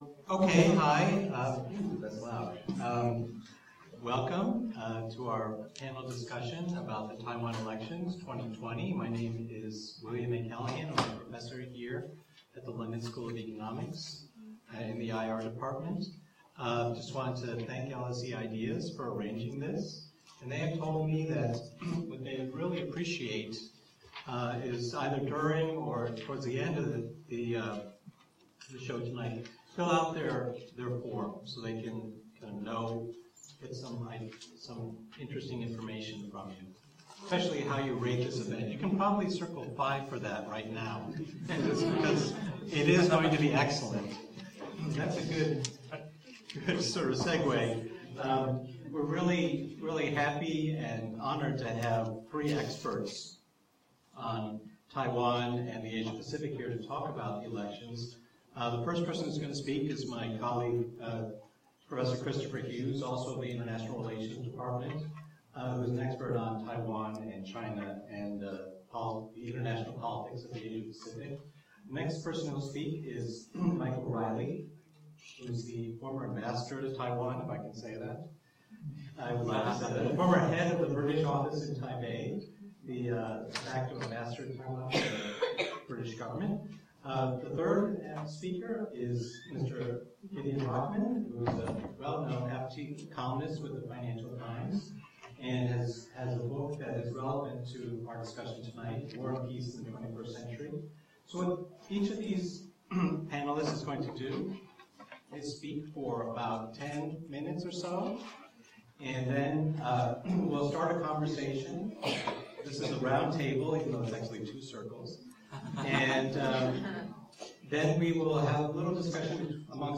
LSE IDEAS hosted a panel discussion on the potential shift in Taiwanese foreign policy ahead of the Taiwan elections in January 2020.